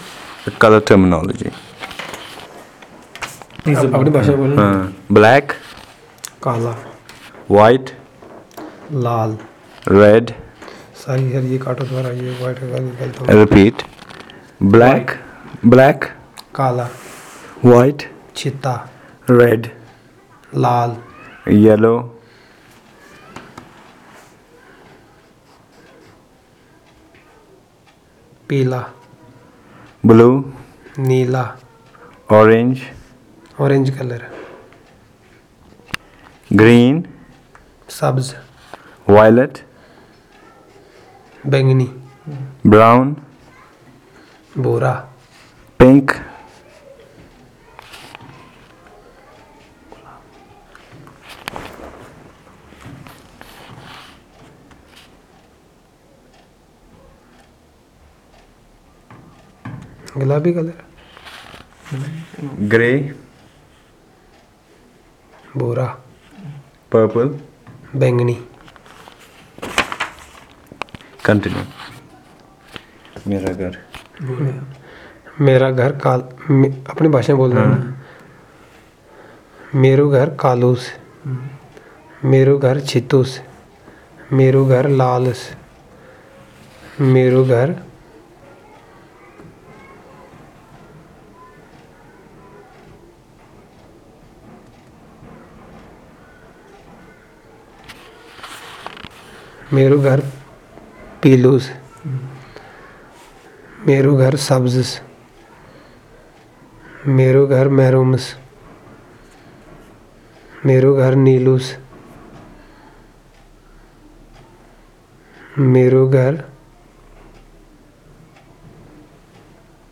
NotesThis is an elicitation of words and sentences about colour terms in Hassadi.